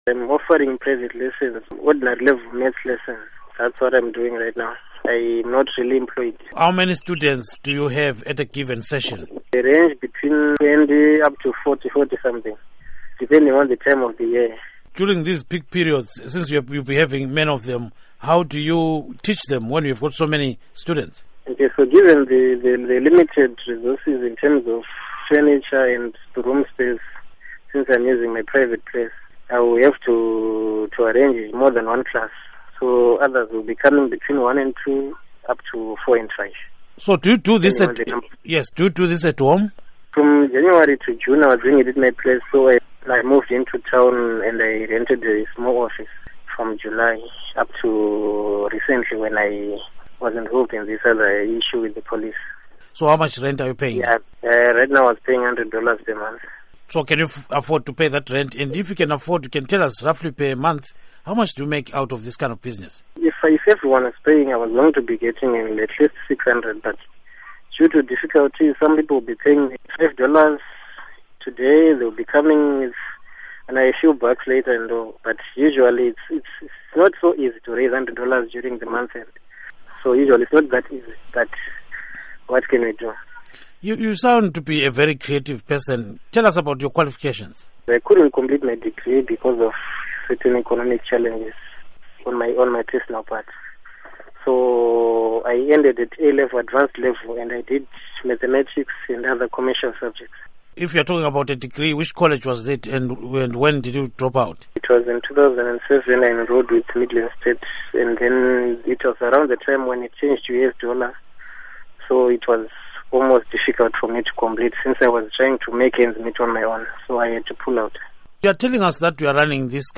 Interview with Former Midlands State University Student